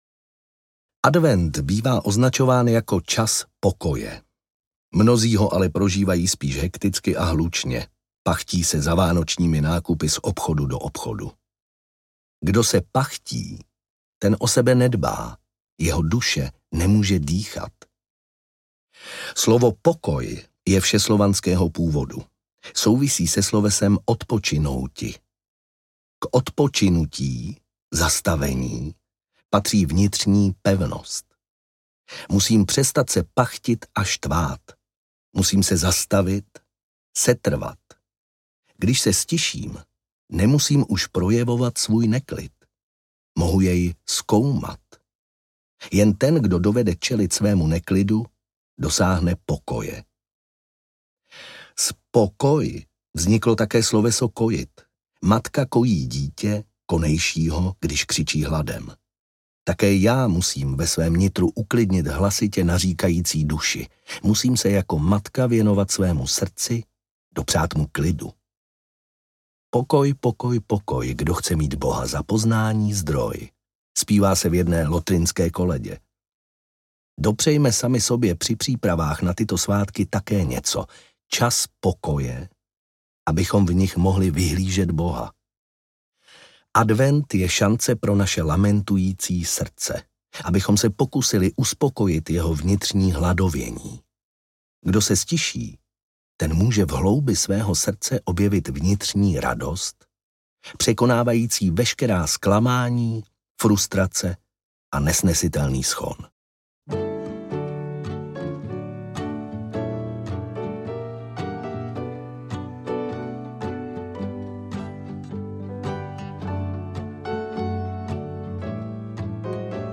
Vánoční rozjímání audiokniha
Ukázka z knihy